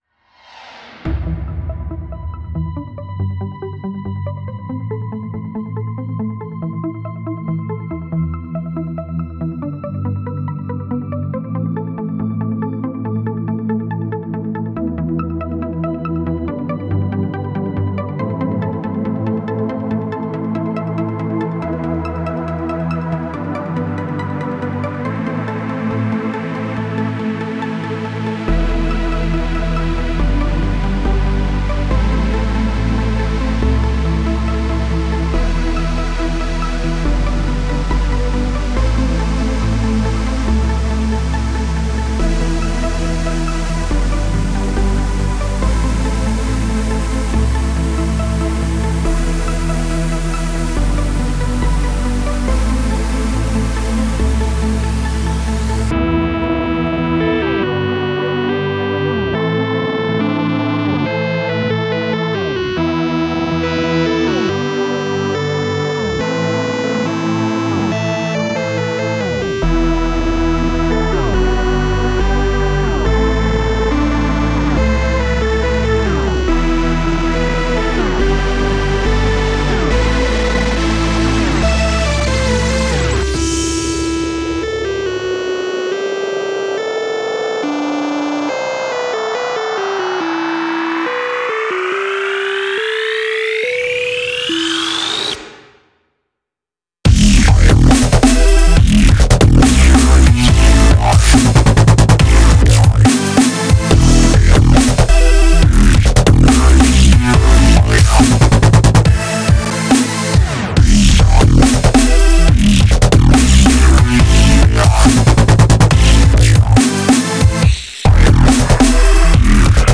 Стиль музыки: Dub Step